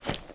STEPS Dirt, Run 04.wav